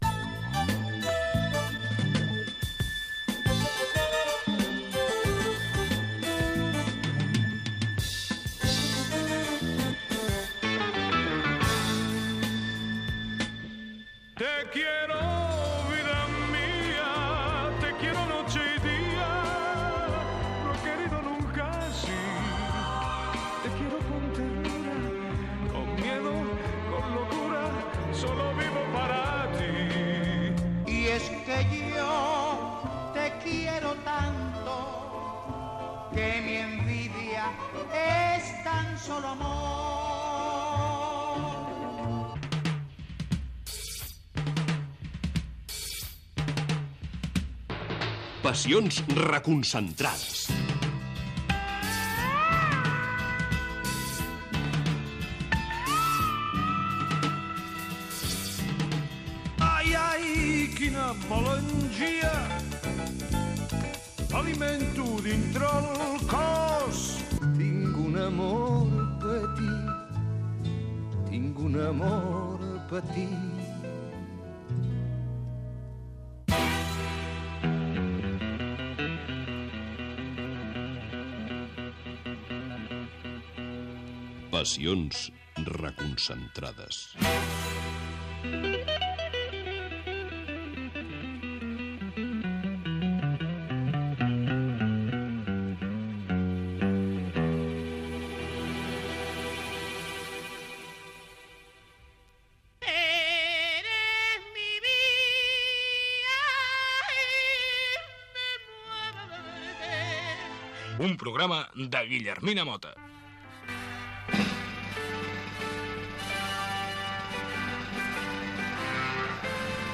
Careta del programa, presentació i entrevista al cantant i actor Ovidi Montllor
Entreteniment